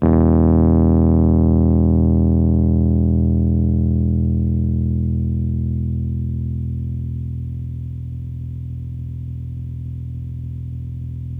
RHODES CL00R.wav